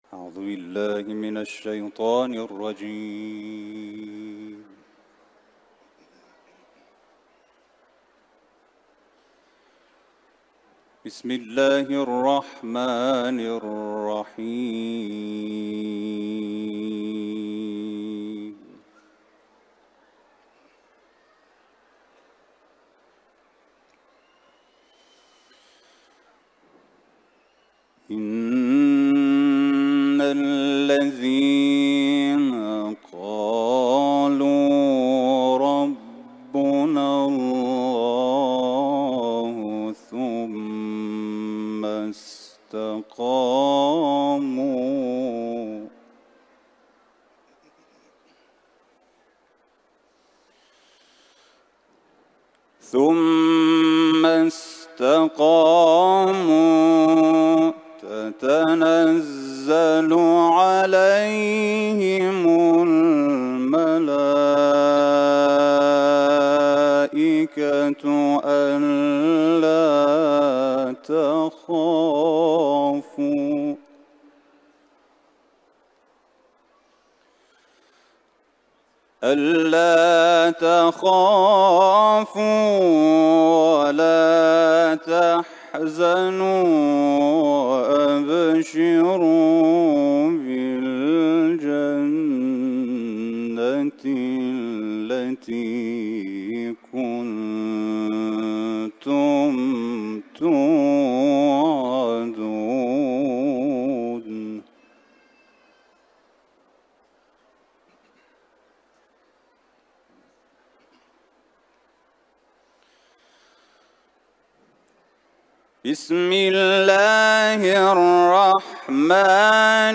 سوره فصلت ، تلاوت قرآن ، حرم مطهر رضوی